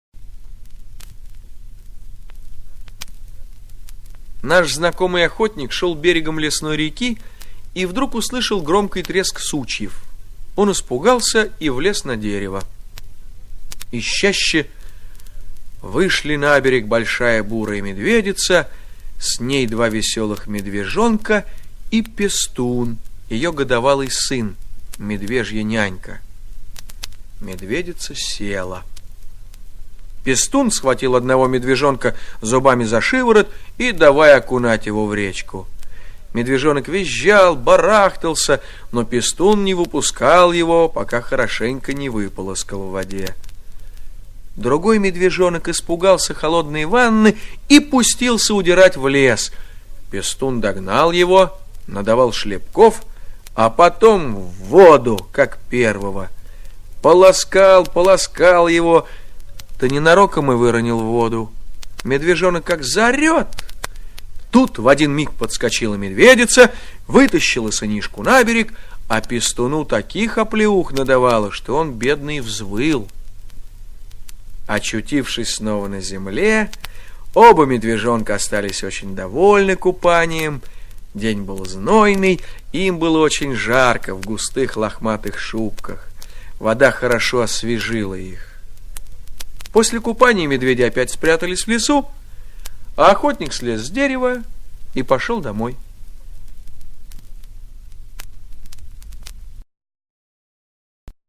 Аудиорассказ «Купание медвежат»
Текст читает Олег Табаков.